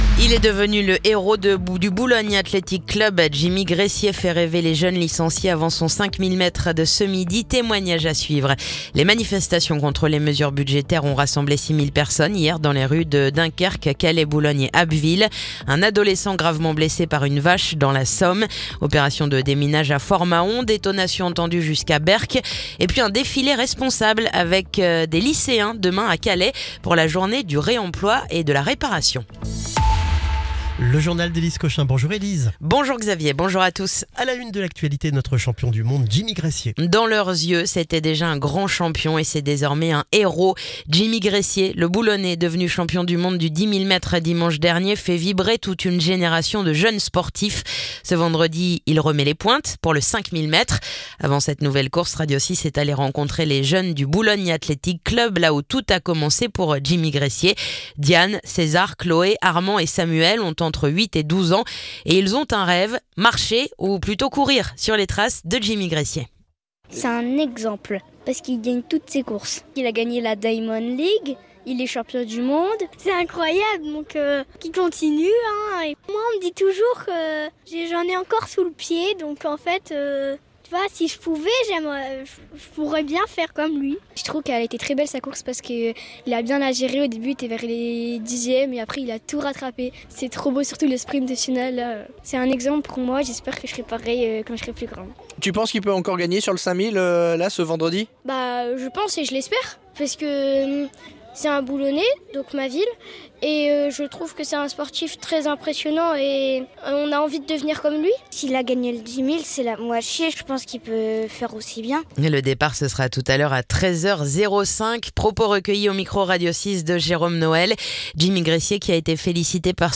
Le journal du vendredi 19 septembre